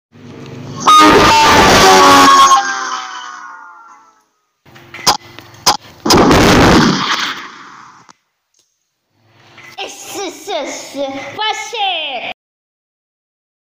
Hidden Windows Vista XP Startup sound effects free download